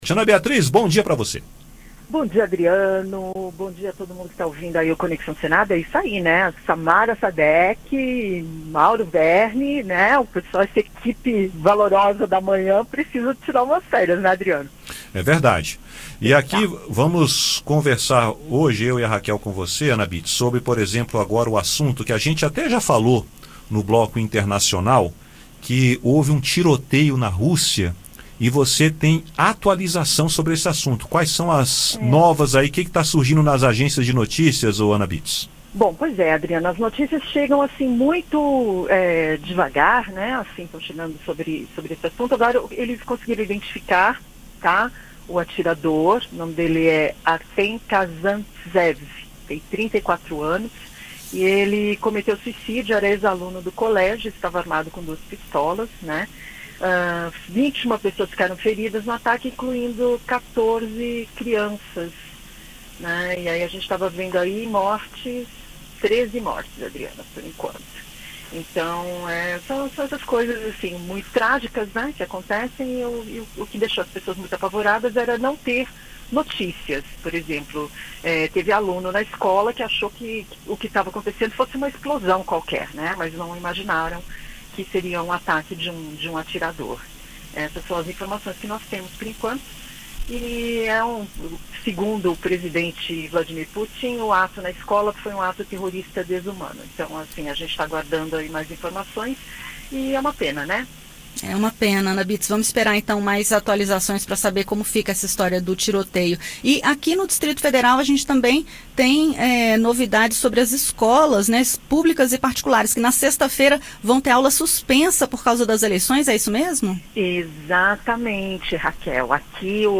A jornalista